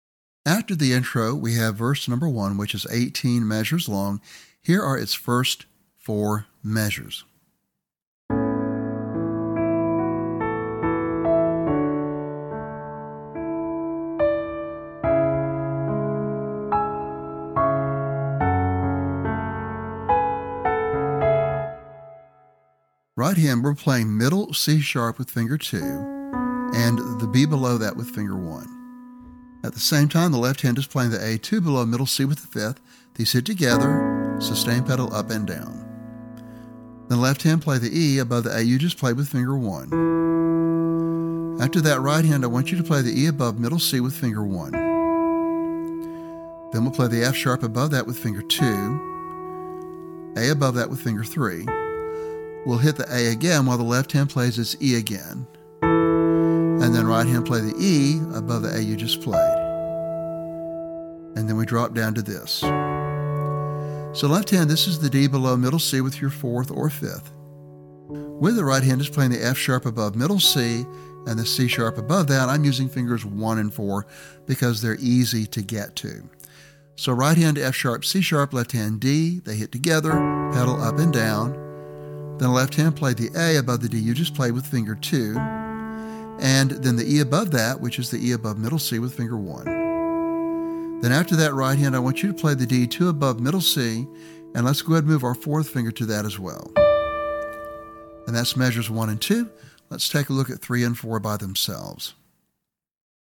beautiful piano arrangement
This song is rated at an intermediate level.